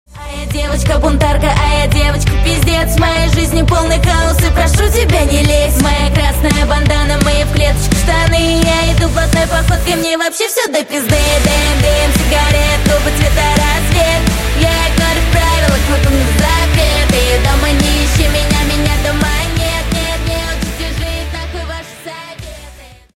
Рок Металл Рингтоны